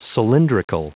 Prononciation du mot cylindrical en anglais (fichier audio)
Prononciation du mot : cylindrical